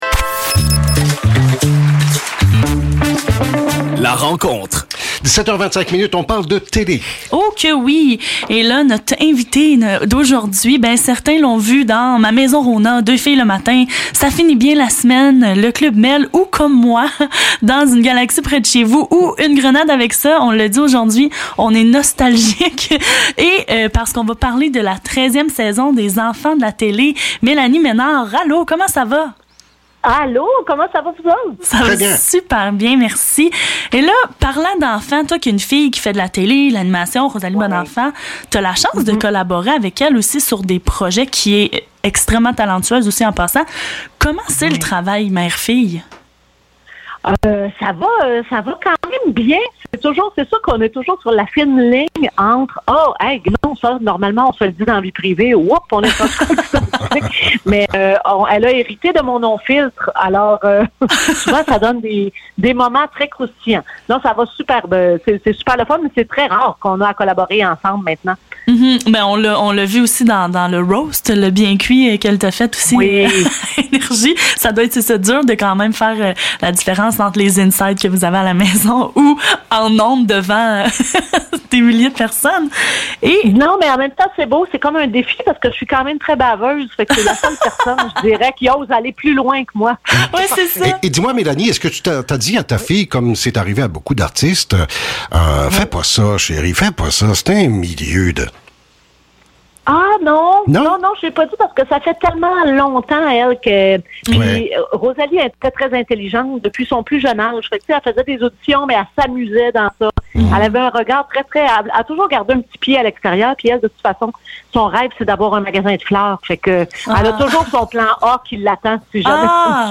Entrevue avec Mélanie Maynard